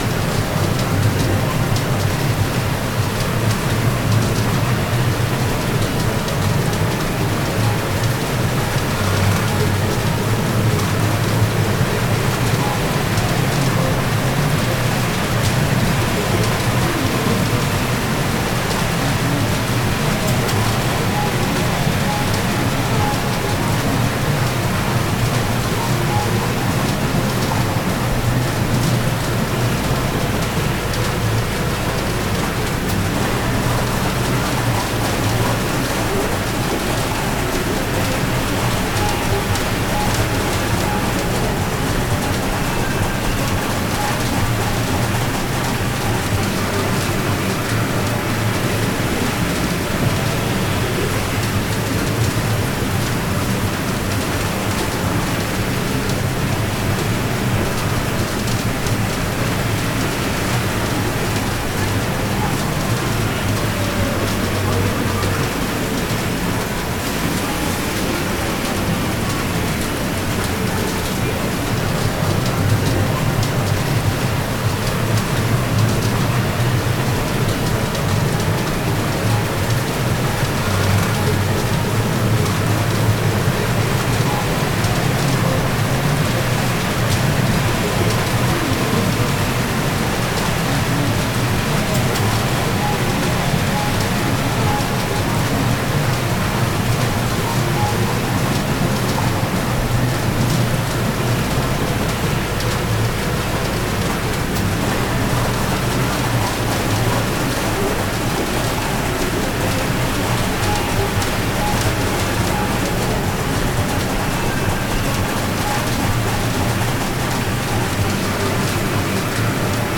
街の環境音１（日常系）
パチンコ２
pachinko2.mp3